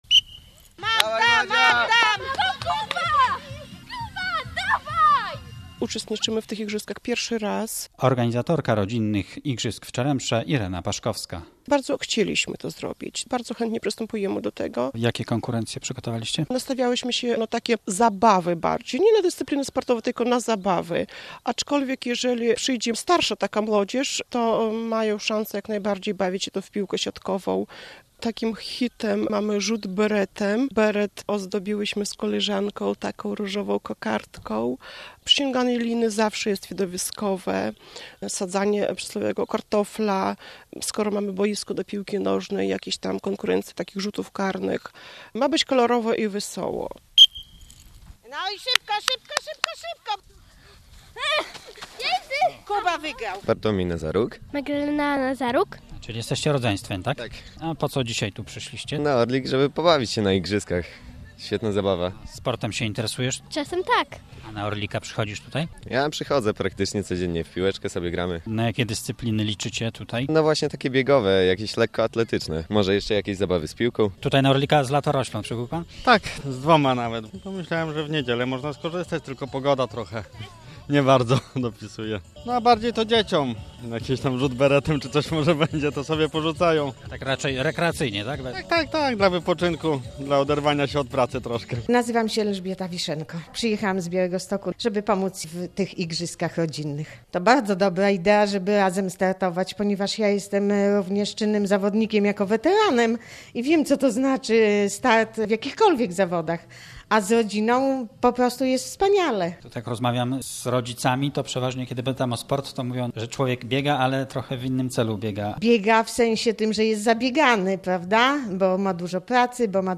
"Rodzinne Igrzyska" na orliku w Czeremsze - relacja